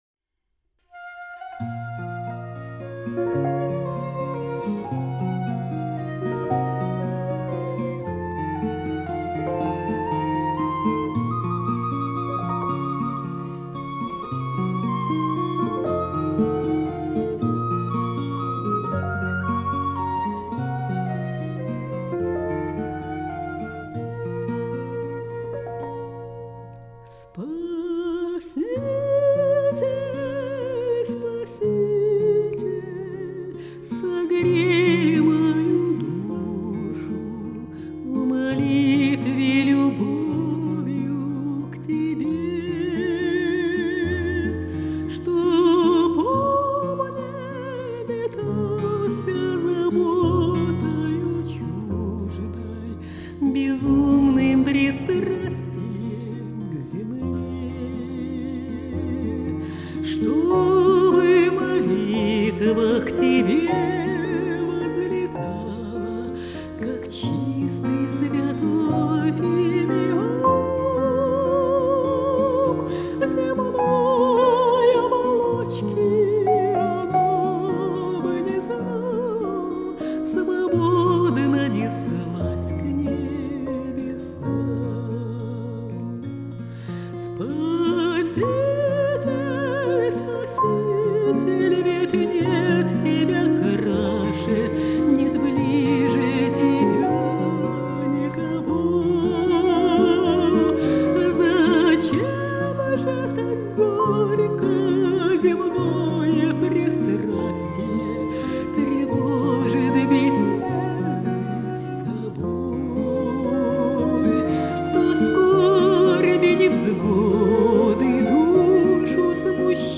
Духовная музыка